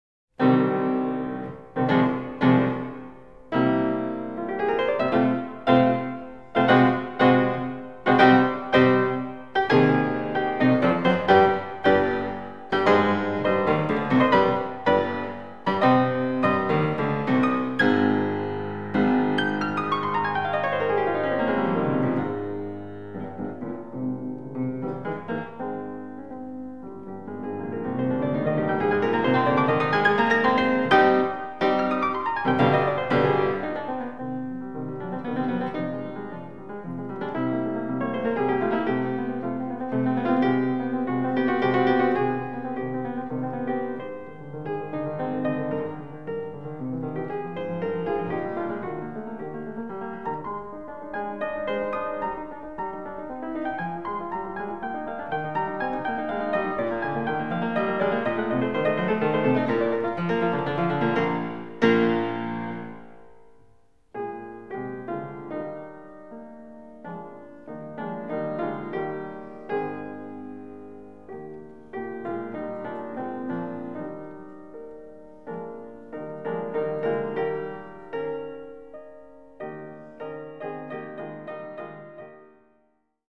Romainmôtier (VD)